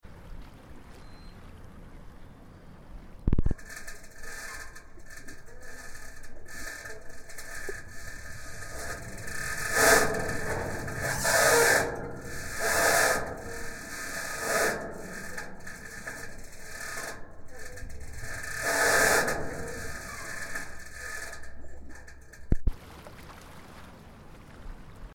The platform isn’t the only thing making noise – the ladder connecting it to the shore has a voice of its own. As the waves rock the structure, vibrations travel through the metal joints and cables, creating a haunting sound. Heads up – it’s loud and a bit harsh.